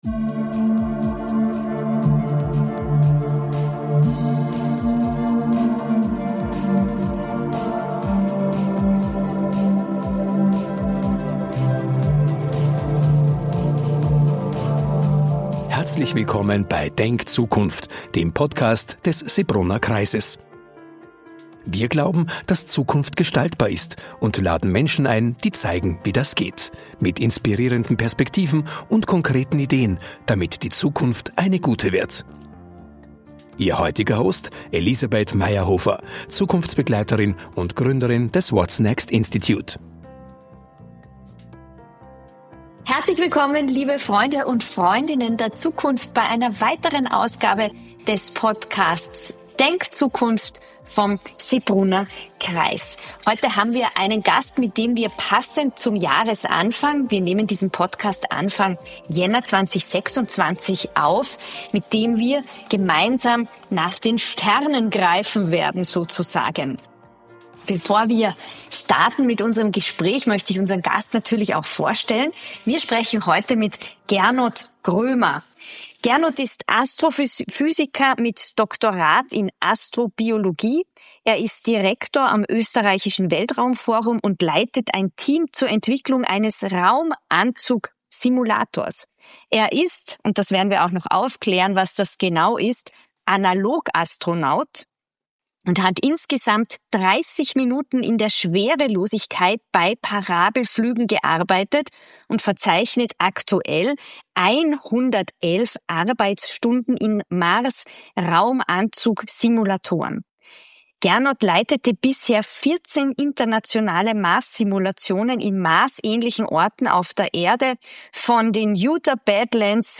Ein inspirierendes Gespräch das mit einem Blick das Lust auf Zukunft macht.